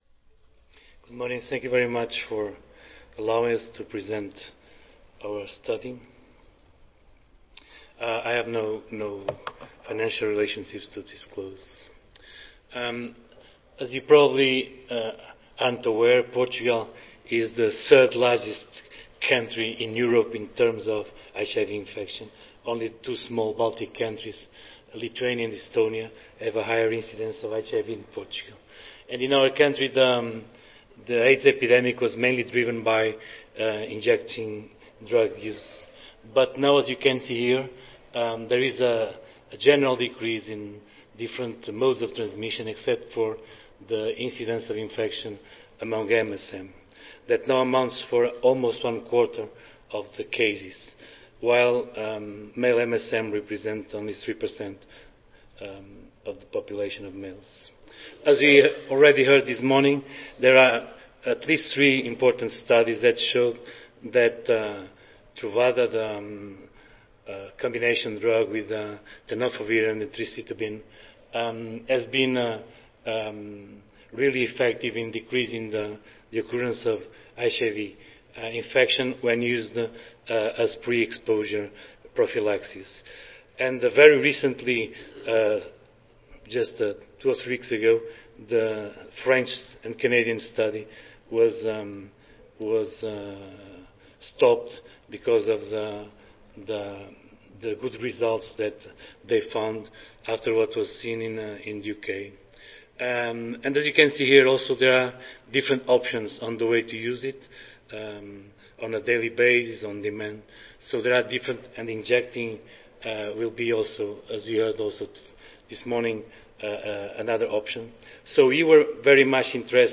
142nd APHA Annual Meeting and Exposition (November 15 - November 19, 2014): Eligibility for PrEP among members of a HIV-negative MSM Portuguese Cohort